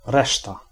Ääntäminen
IPA : /ɹɛst/